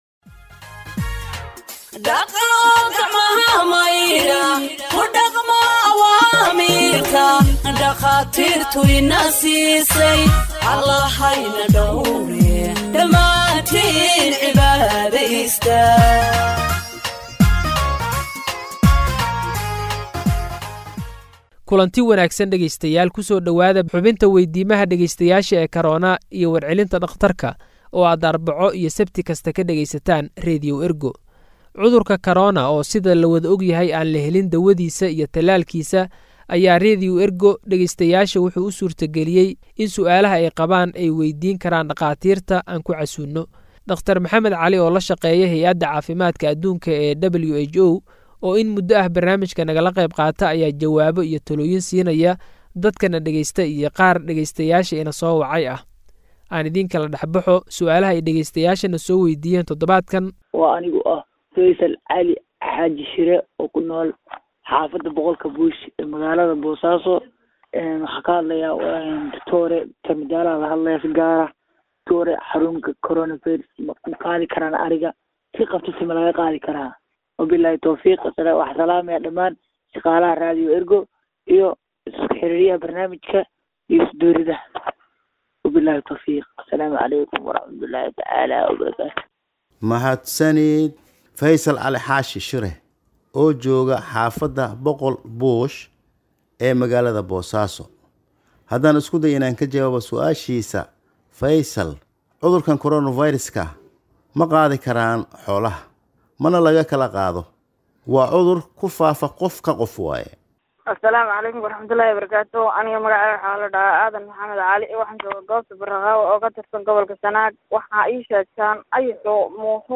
Health expert answers listeners’ questions on COVID 19 (33)